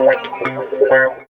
110 GTR 3 -L.wav